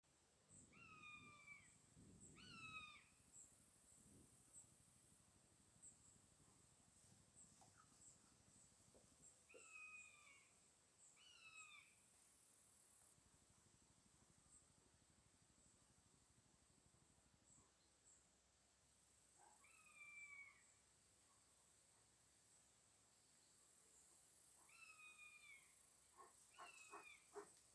Common Buzzard, Buteo buteo
Administratīvā teritorijaPriekuļu novads
StatusVoice, calls heard
NotesVai varētu būt jaunie putni? Divas dienas aptuveni vienā vietā sasaucās.